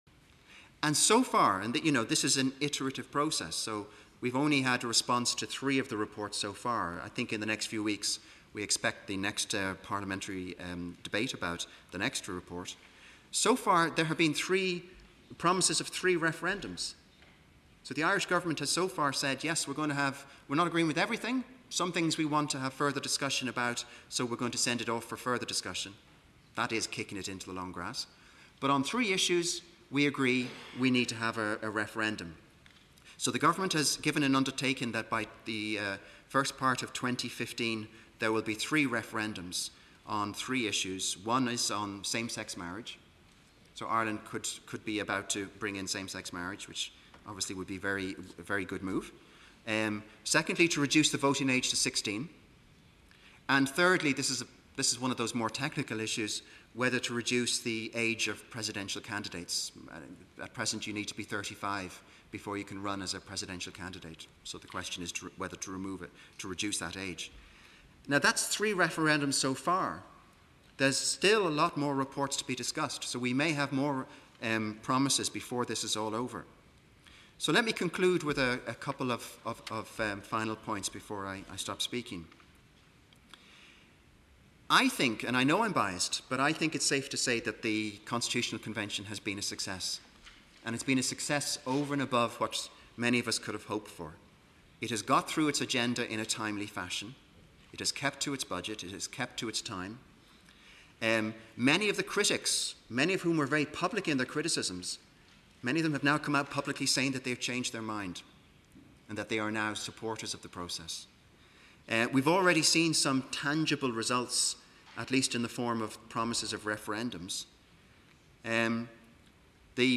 Colloque 23/01/2014 : SP 4